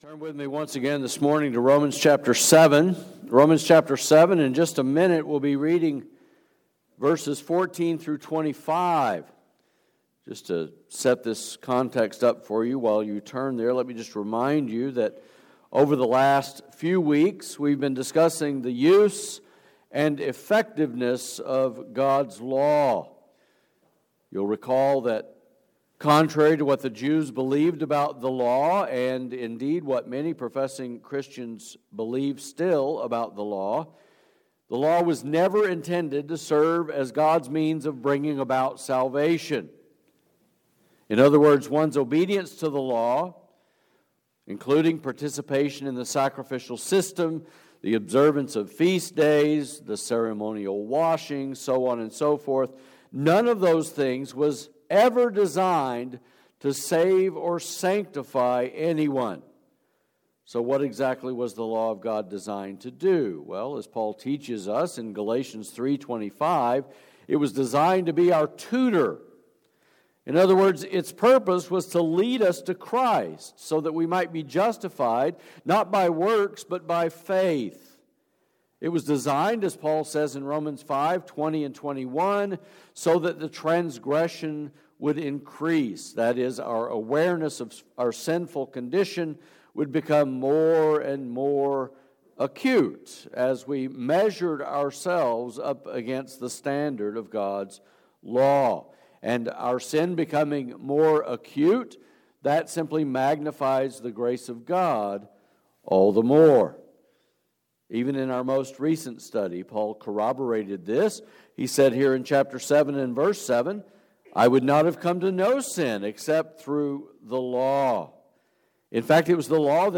Sermons | Grace Baptist Church San Antonio